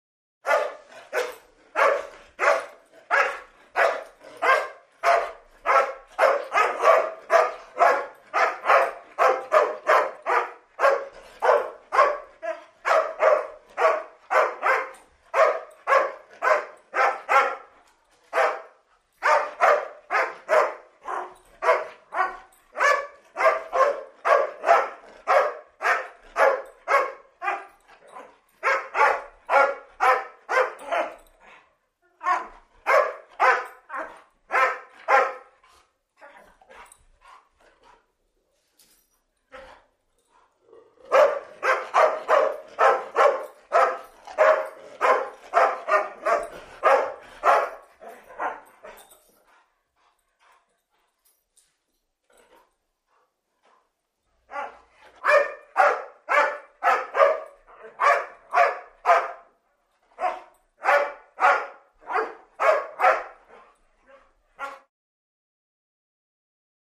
DogDobermanBarksHi AT020901
Dog, Doberman Barks. High Pitched, Reverberant, Snarl-like Barks With Light Pants And Whines. Medium Perspective.